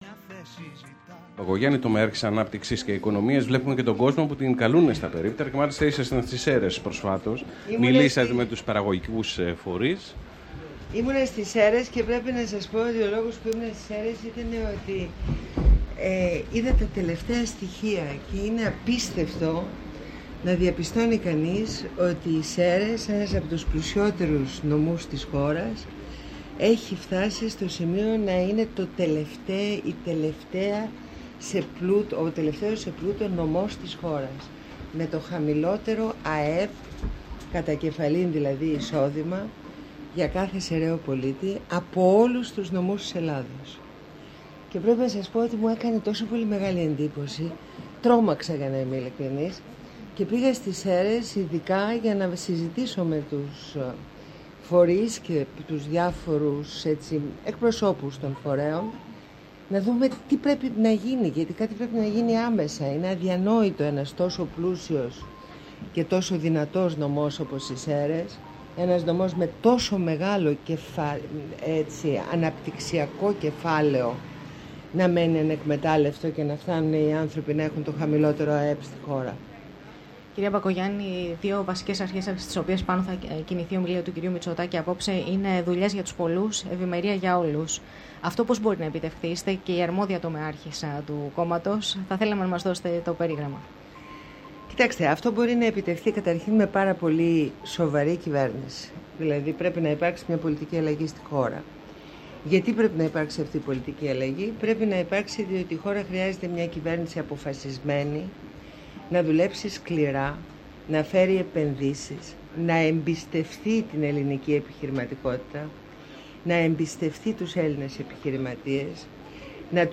Συνέντευξη στο Πρακτορείο FM 104.9